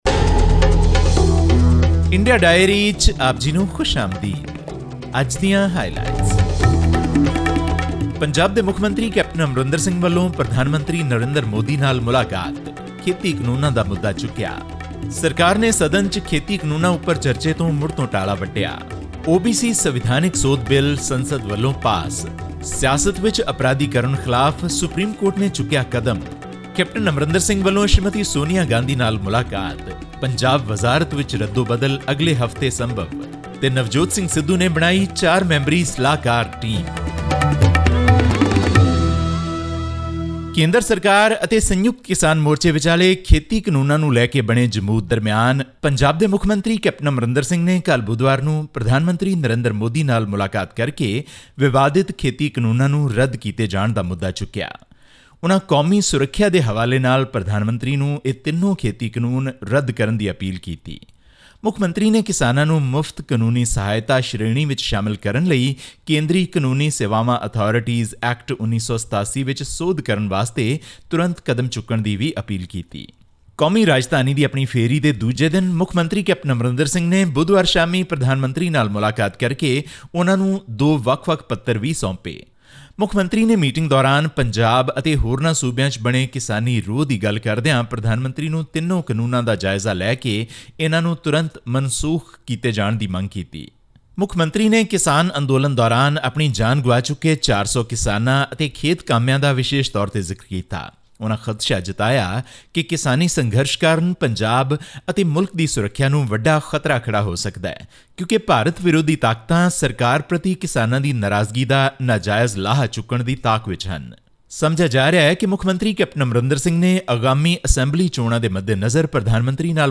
Punjab chief minister Captain Amarinder Singh on 13 August, met Prime Minister Narendra Modi in New Delhi, calling for immediate review and revocation of the three controversial farm laws that have triggered the biggest farmers' protest on the outskirts of New Delhi since November last year. All this and more in our weekly news segment from India.